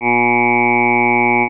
The next sound is Ooh, as in the oo in Food.
ooh.wav